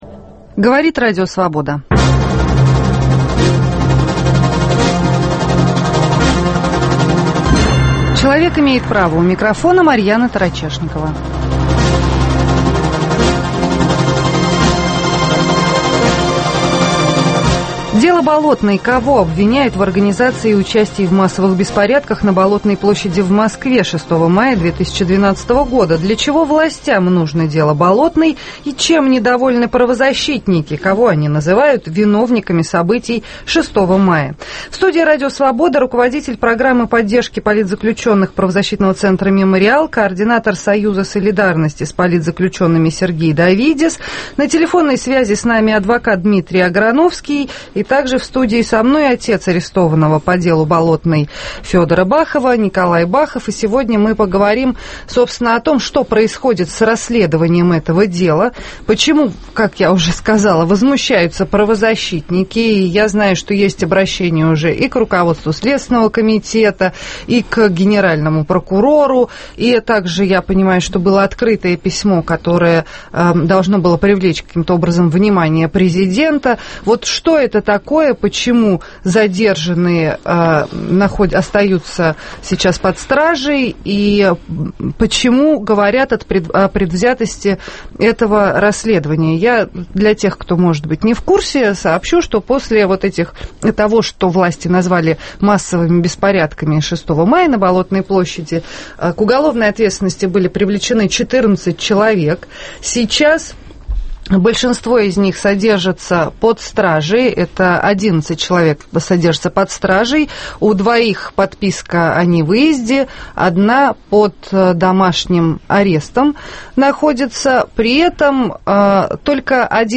В студии РС